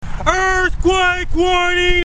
EarthQuake Warning